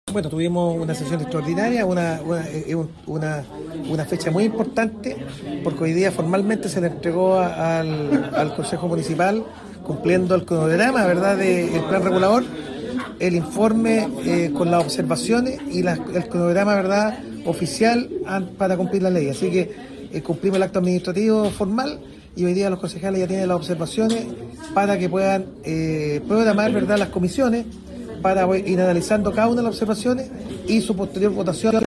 El alcalde Nelson Estay subrayó la relevancia del momento: